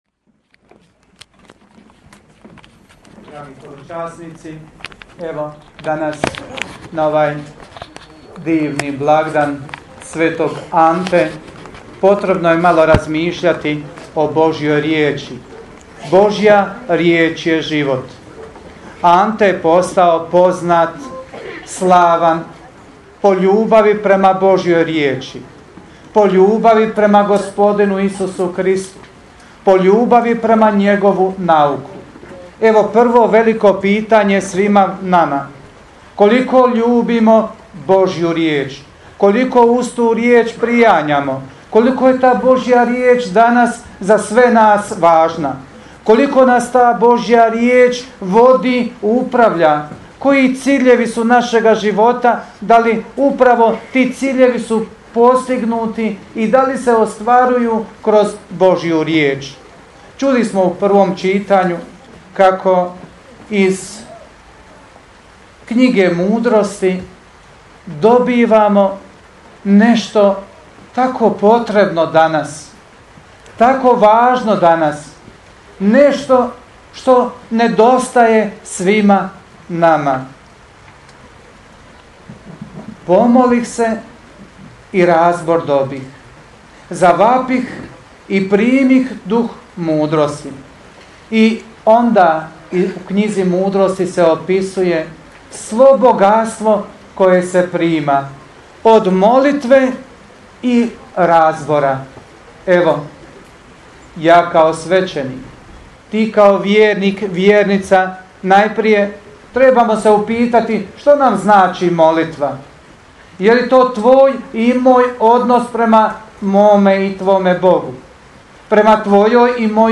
PROPOVIJED